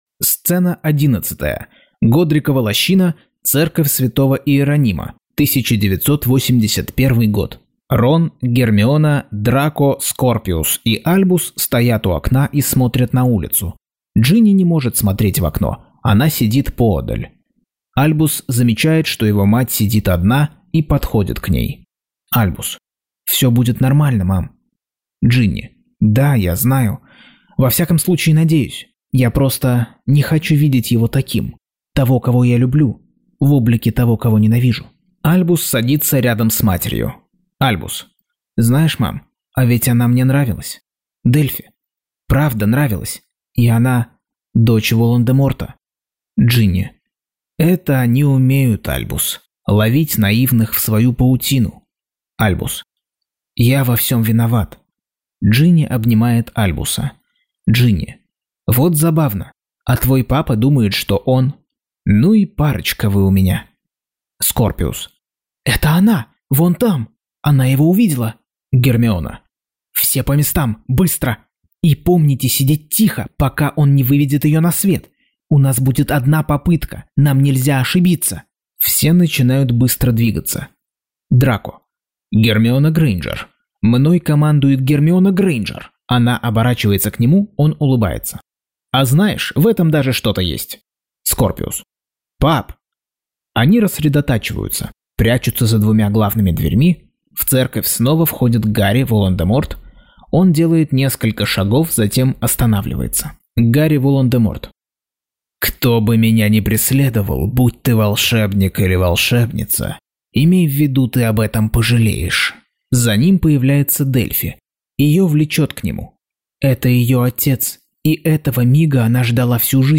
Аудиокнига Гарри Поттер и проклятое дитя. Часть 62.